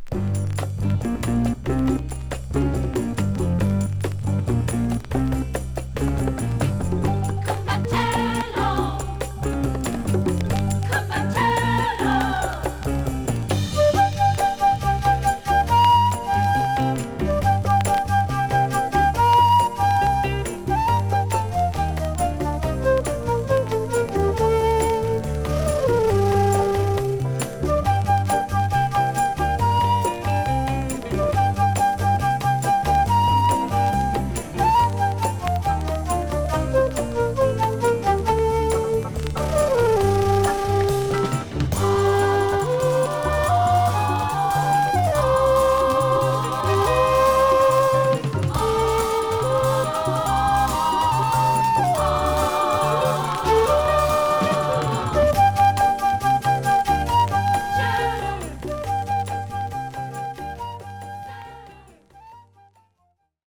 ドボルザーク新世界より「家路」の尺八イージーリスニング・ファンクカバー◎ 奇妙なジャパニーズ・モンド・レコード中南米編。